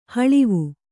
♪ haḷivu